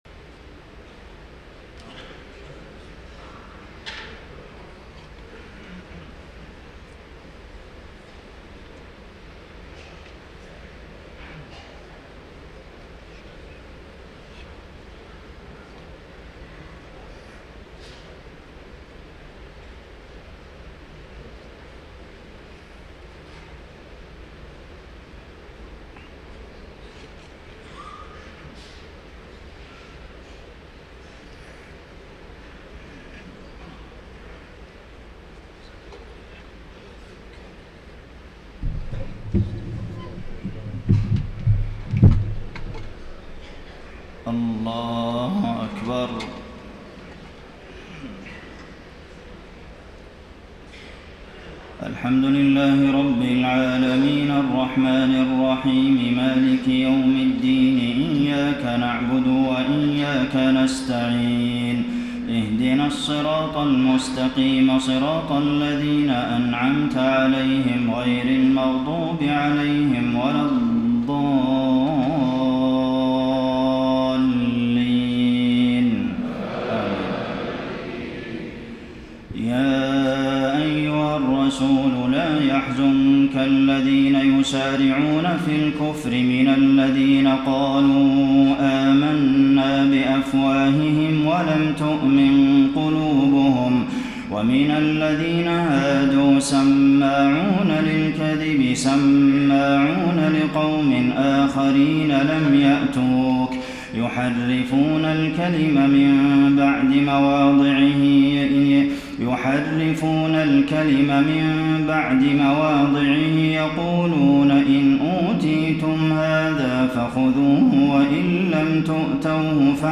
تهجد ليلة 26 رمضان 1433هـ من سورة المائدة (41-81) Tahajjud 26 st night Ramadan 1433H from Surah AlMa'idah > تراويح الحرم النبوي عام 1433 🕌 > التراويح - تلاوات الحرمين